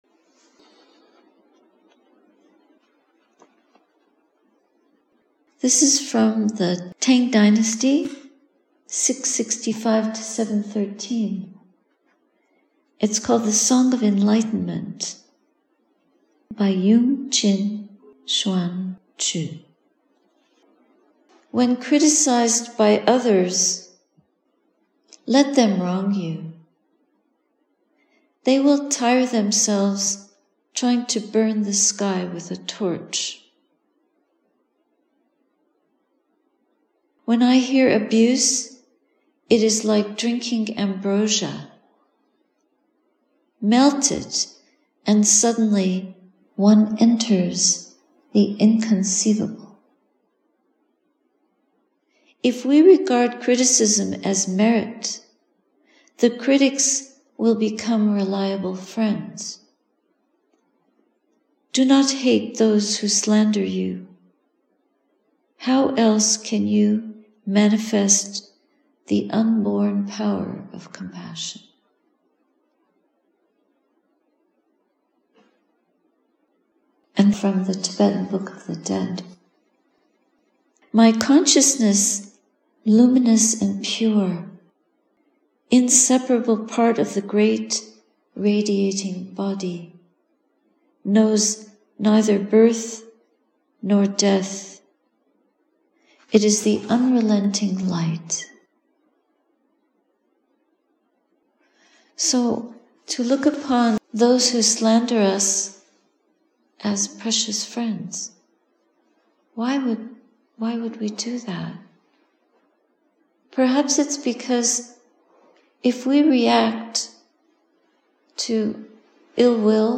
Sati Saranya Hermitage, Nov. 5, 2023